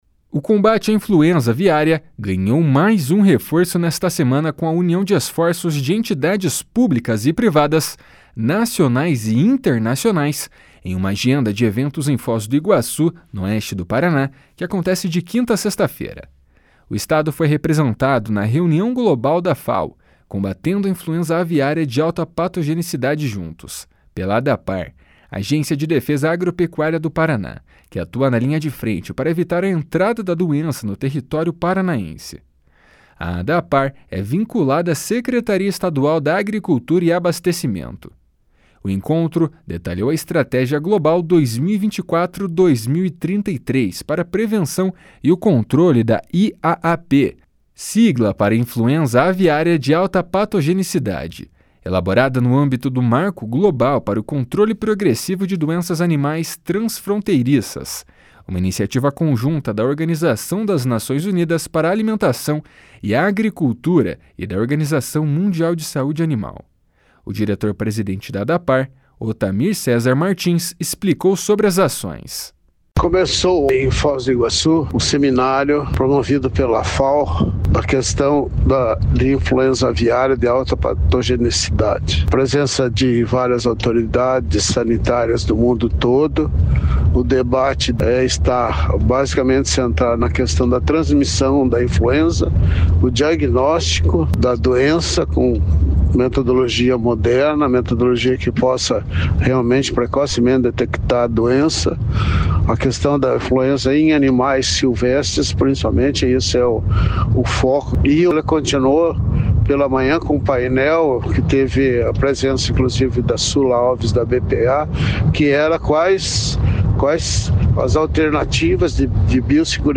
O encontro detalhou a Estratégia Global 2024–2033 para a Prevenção e o Controle da IAAP, sigla para Influenza Aviária de Alta Patogenidade, elaborada no âmbito do marco global para o controle progressivo de doenças animais transfronteiriças, uma iniciativa conjunta da Organização das Nações Unidas para a Alimentação e a Agricultura e da Organização Mundial de Saúde Animal. O diretor-presidente da Adapar, Otamir Cesar Martins, explicou sobre as ações.